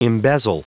Prononciation du mot embezzle en anglais (fichier audio)
Prononciation du mot : embezzle